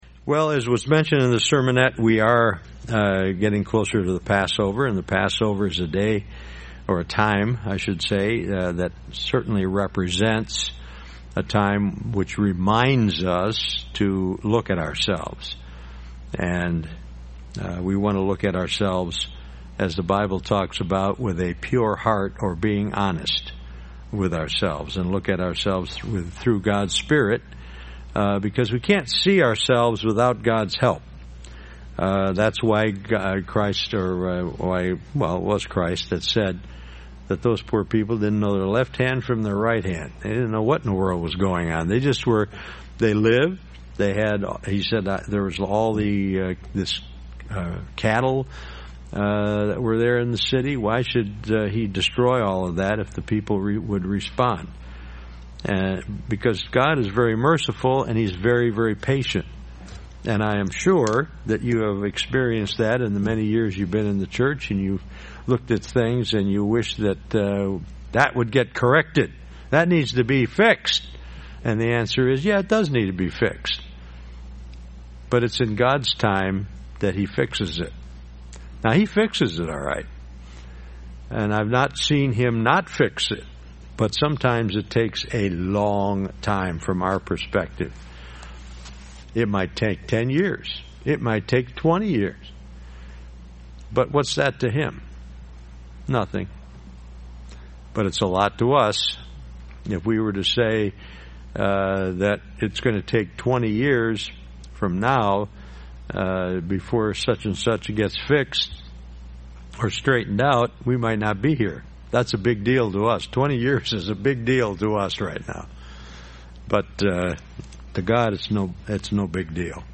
Given in Beloit, WI
UCG Sermon Studying the bible?